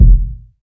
KICK.57.NEPT.wav